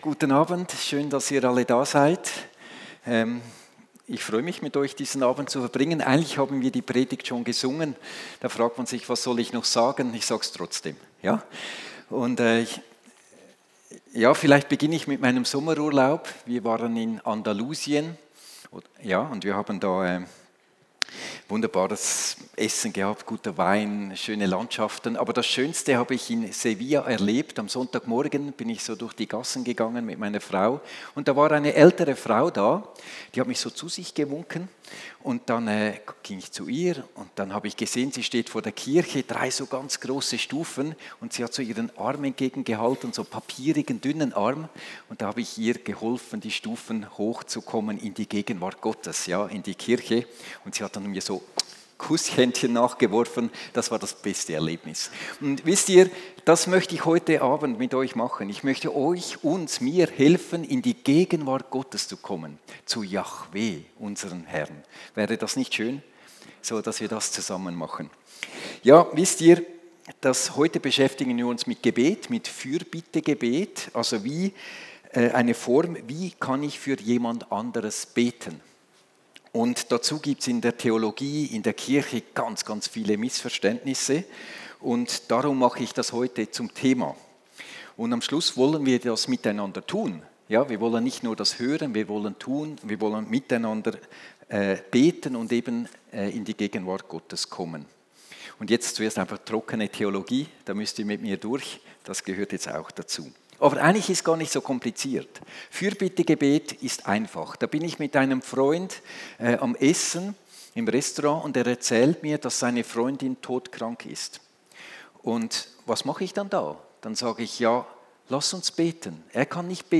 Heilungsgottesdienst - Fürbitte - Kirche Lindenwiese
Predigt als Audio